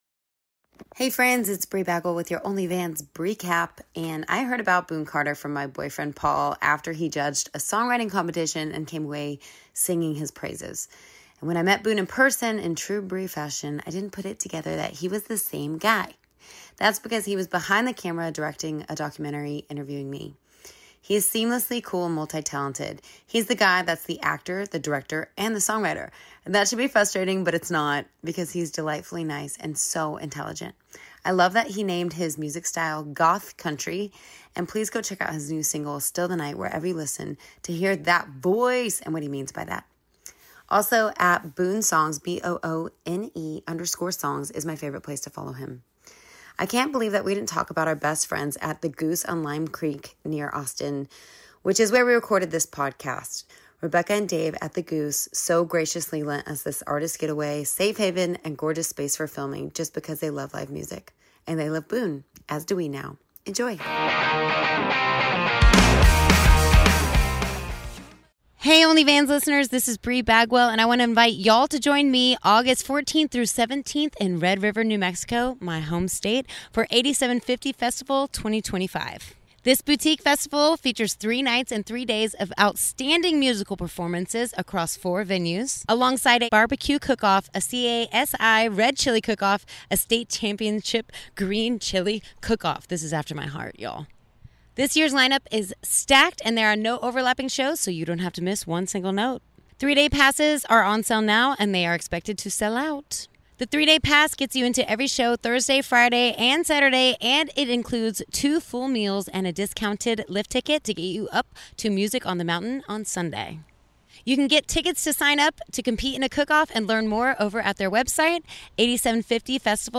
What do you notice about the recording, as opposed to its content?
Contemporary 40:31 Play Pause 3d ago 40:31 Play Pause Play later Play later Lists Like Liked 40:31 As we approach the Fourth of July, we invite you to join us for a special service centered not on one nation’s independence, but rather on God’s sovereign hand throughout history.